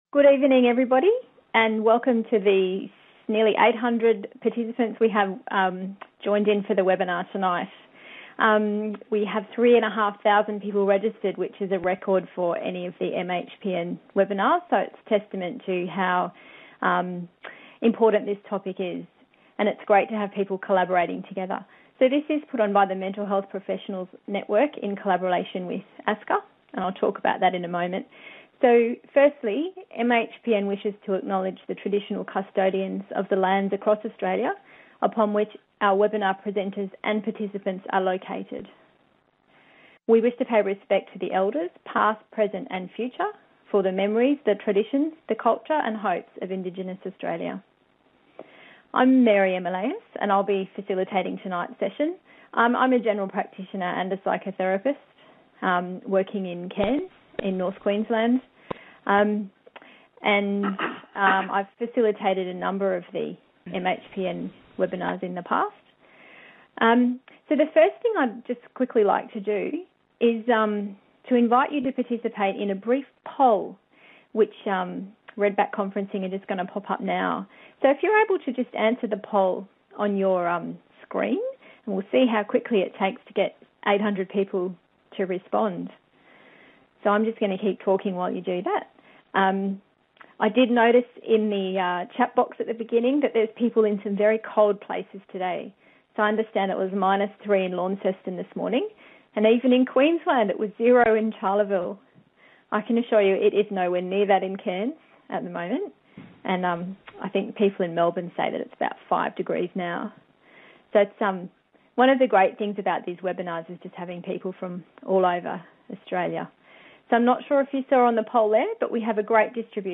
Join our interdisciplinary panel of experts for a discussion about supporting the wellbeing of people experiencing a trauma response.